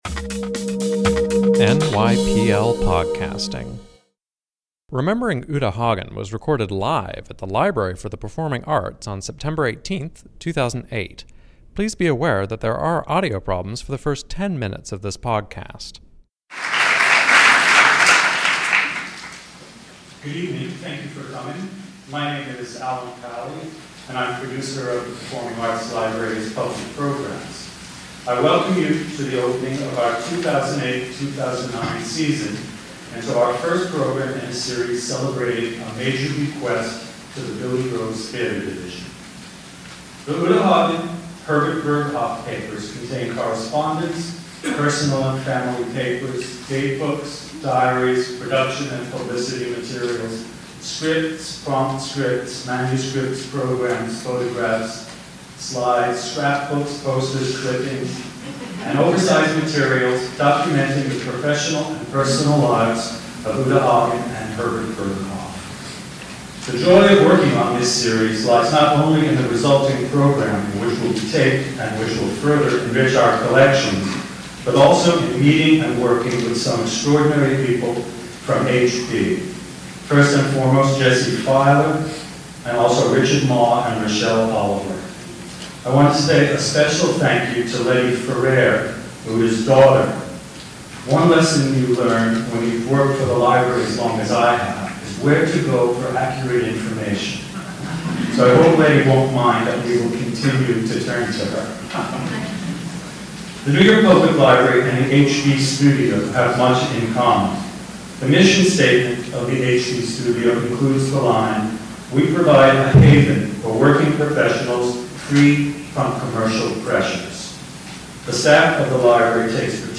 Remembering Uta Hagen: A Panel Discussion Featuring Barbara Barrie
As part of the series A Challenge for the Artist: Uta Hagen and Herbert Berghof in the American Theater, this event on September 18, 2008, featured Barbara Barrie, Richard Easton, Hal Prince, and Fritz Weaver reminiscing about the life and work of Uta Hagen.
(Please note that there are audio problems during the first 10 minutes of this program.)